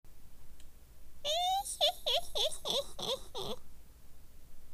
ガヤ②(おばあさん?声)